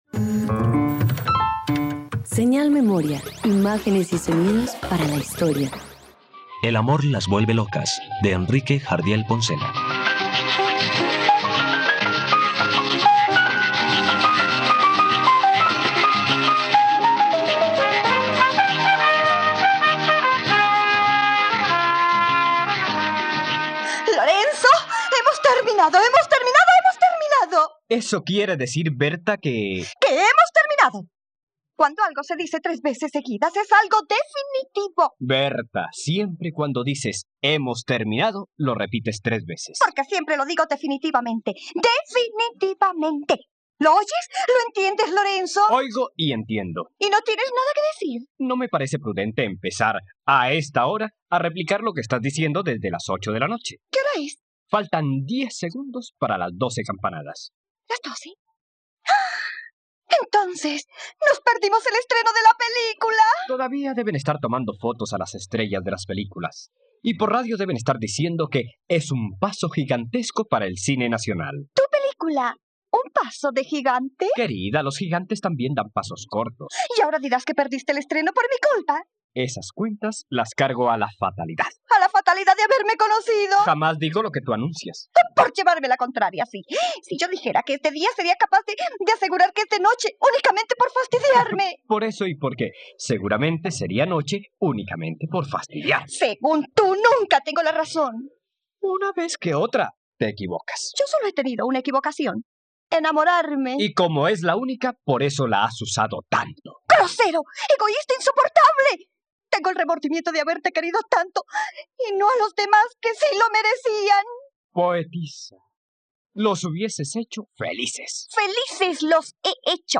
..Radioteatro. Escucha la adaptación radiofónica de “El amor las vuelve locas” de Jadiel Poncela por la plataforma streaming RTVCPlay.